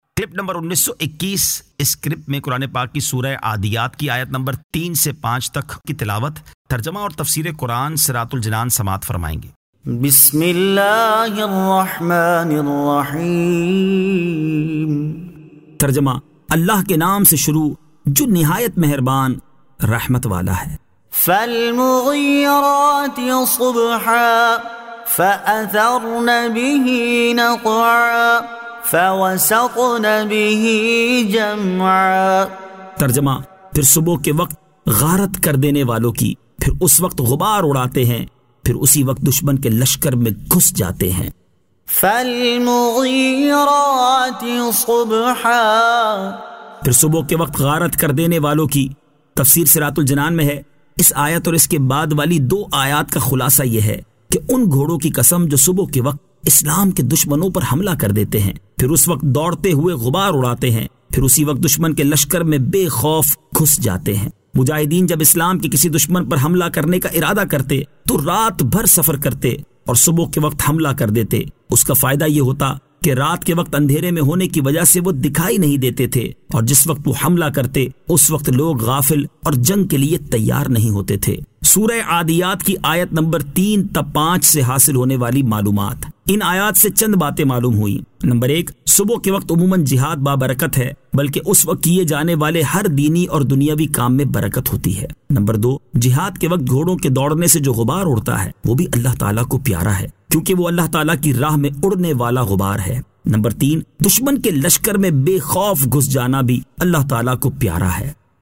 Surah Al-Adiyat 03 To 05 Tilawat , Tarjama , Tafseer